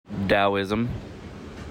Taoism or Daoism ( /ˈt.ɪzəm/ , /ˈd.ɪzəm/